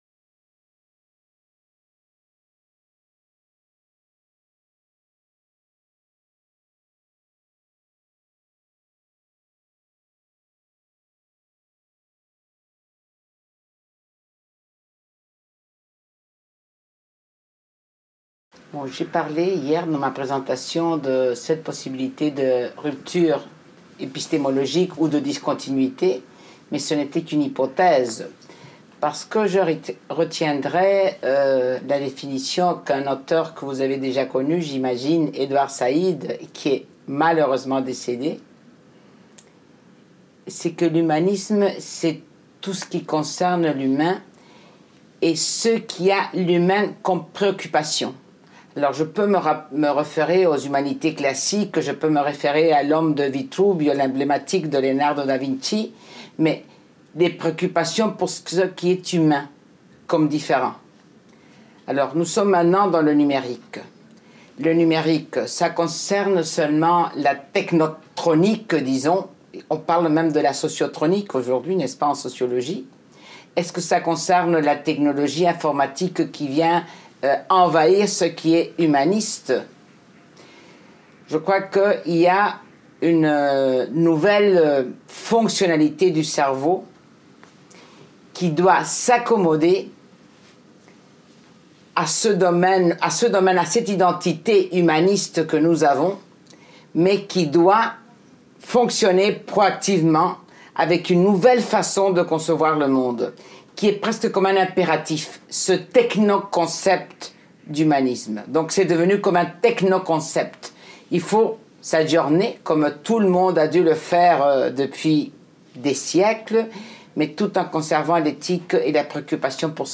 Entretien
réalisé dans le cadre du Symposium international Orbicom "Humanisme Numérique" 2016